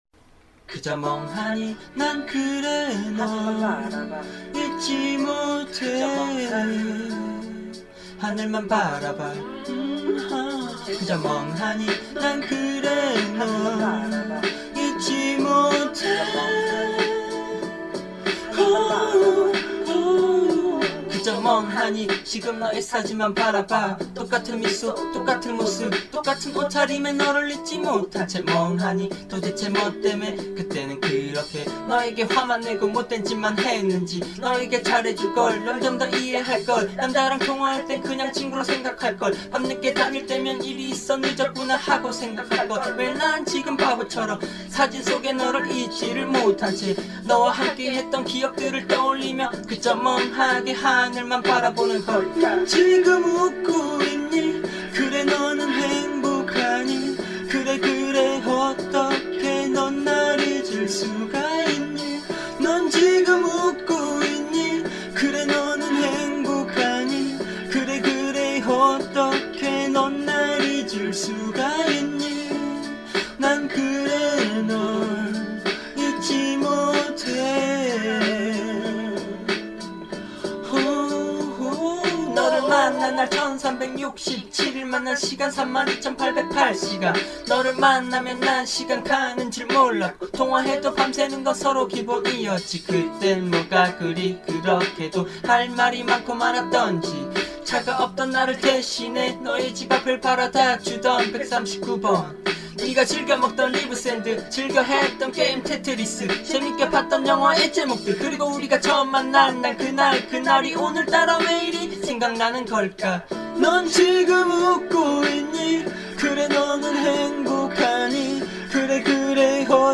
직접 부른 노래를 올리는 곳입니다.